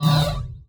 sci-fi_shield_device_small_03.wav